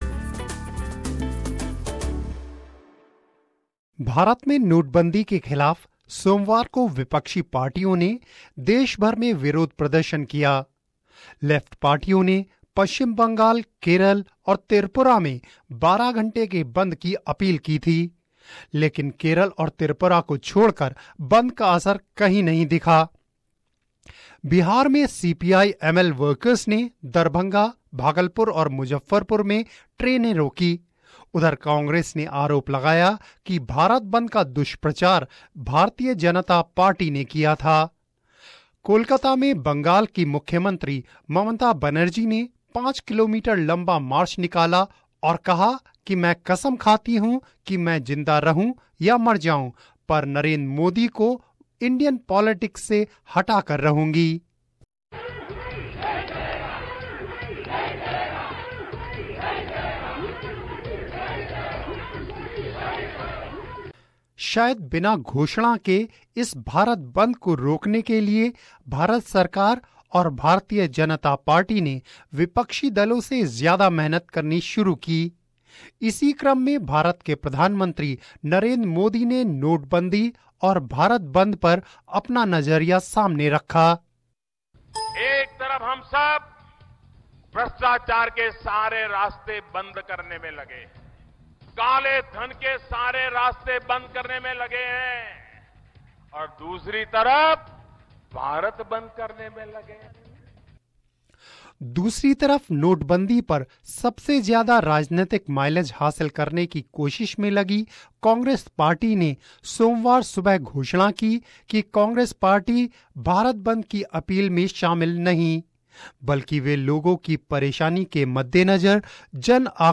रिपोर्ट